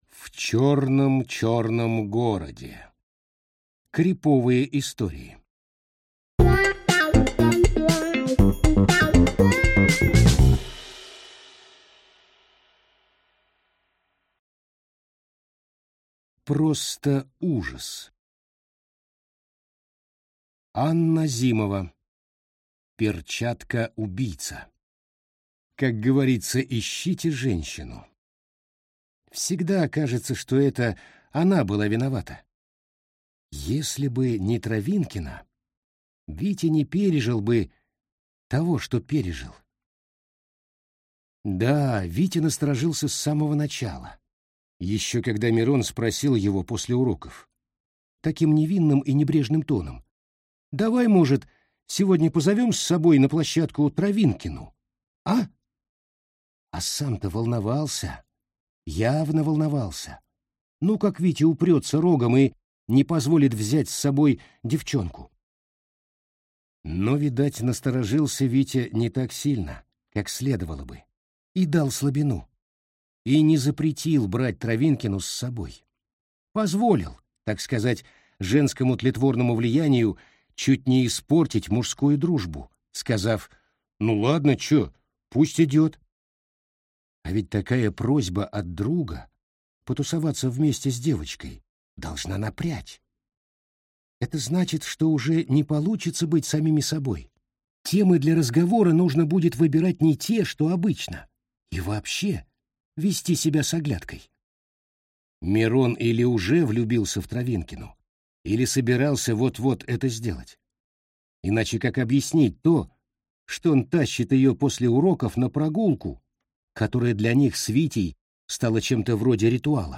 Аудиокнига В черном-черном городе. Криповые истории | Библиотека аудиокниг